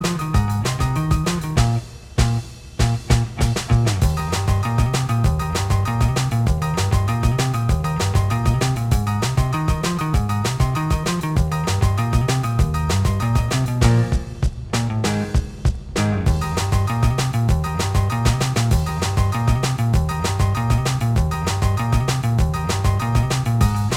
Minus Guitars Pop (1960s) 1:50 Buy £1.50